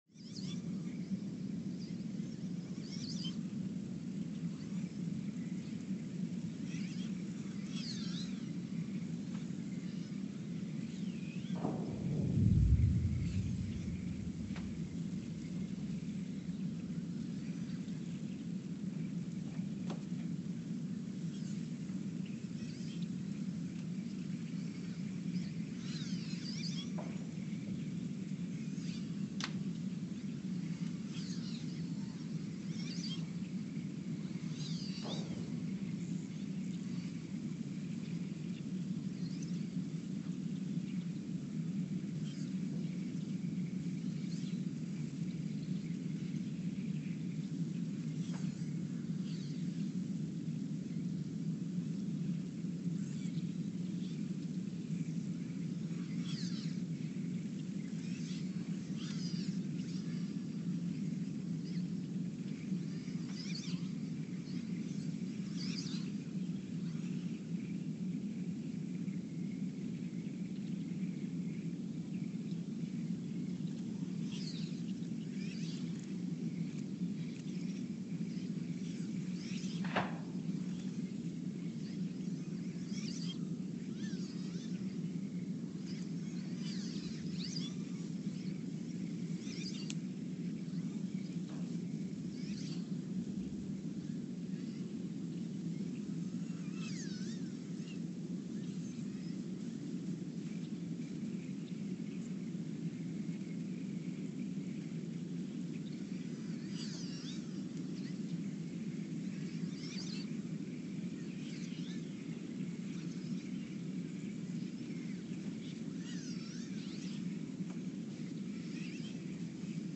The Earthsound Project: Ulaanbaatar, Mongolia (seismic) archived on November 20, 2020
The Earthsound Project is an ongoing audio and conceptual experiment to bring the deep seismic and atmospheric sounds of the planet into conscious awareness.
Sensor : STS-1V/VBB Recorder : Quanterra QX80 @ 20 Hz
Speedup : ×900 (transposed up about 10 octaves)
Loop duration (audio) : 11:12 (stereo)